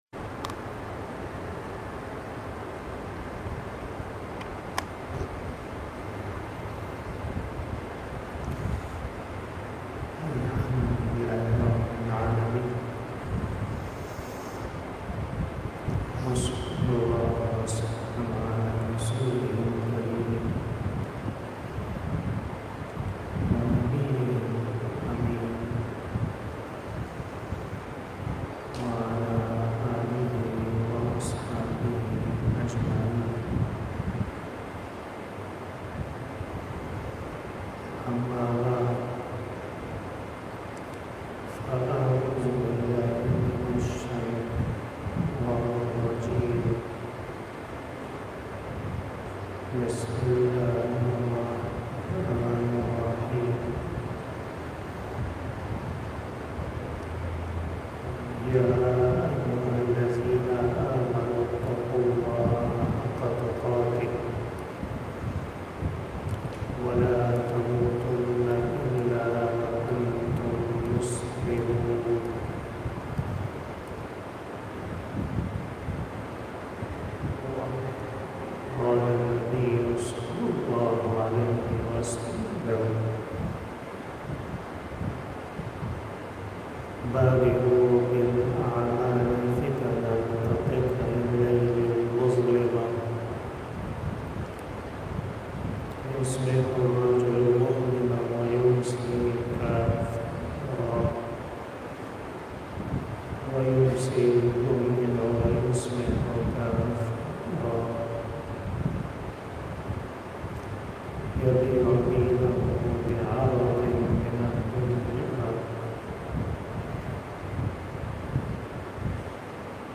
بیان جمعۃ المبارک
03:42 PM 289 Khitab-e-Jummah 2023 --